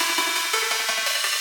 SaS_Arp04_170-A.wav